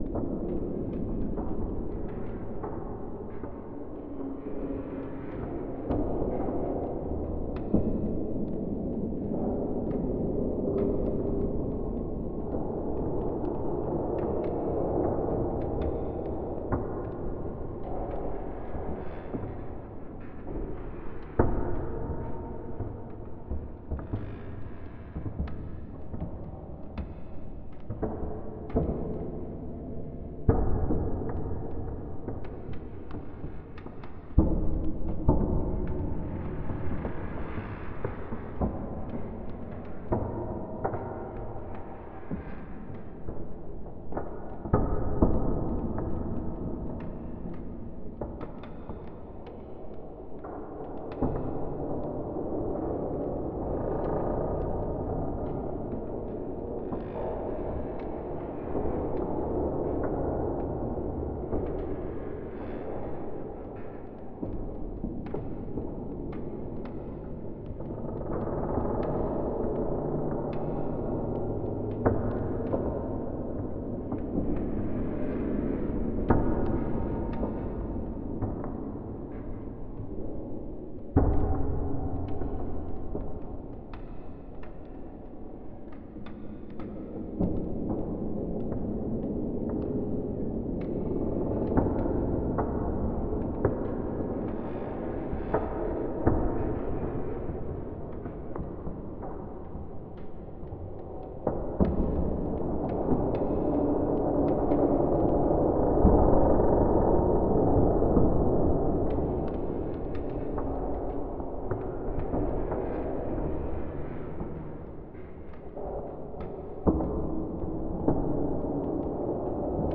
Музыка атмосферы покинутого производства